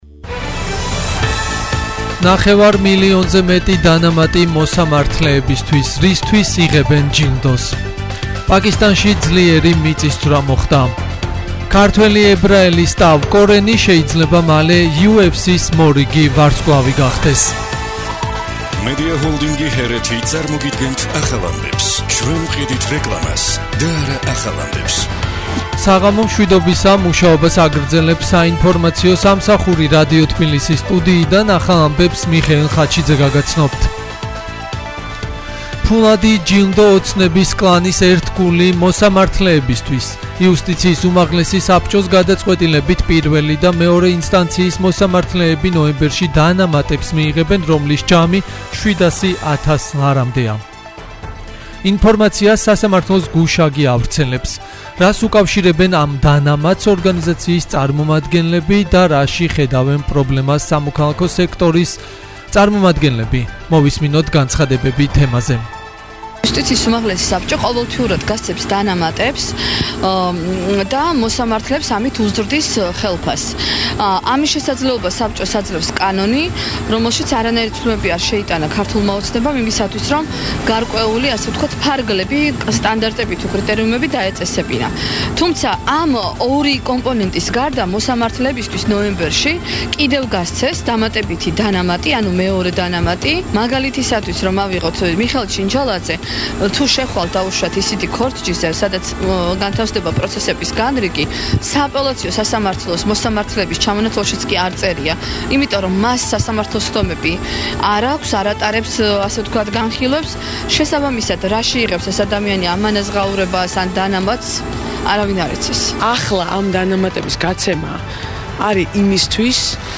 ახალი ამბები 20:00 საათზე - HeretiFM